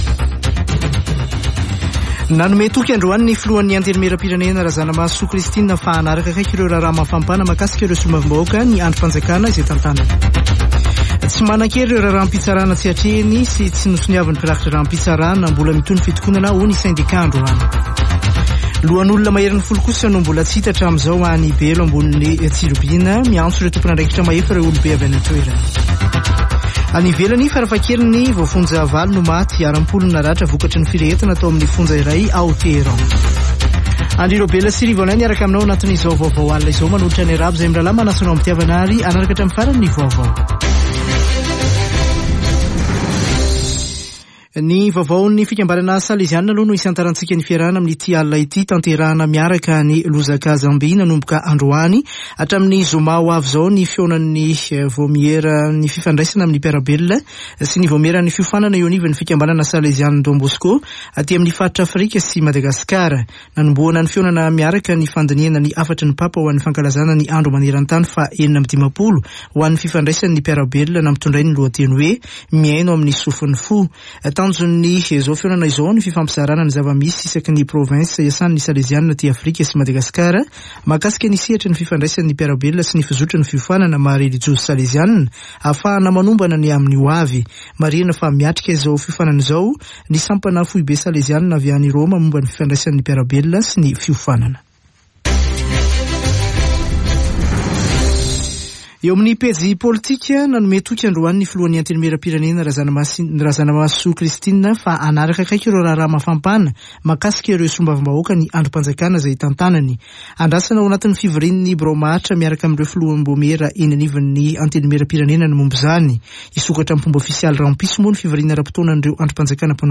[Vaovao hariva] Alatsinainy 17 ôktôbra 2022